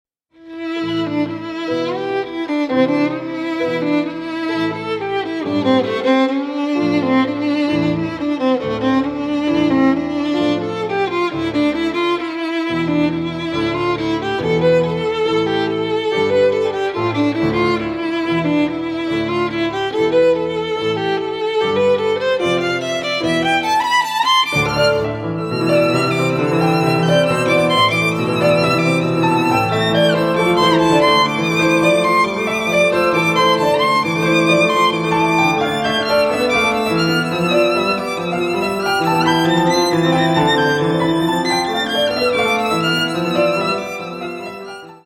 Allegro con moto (7:05)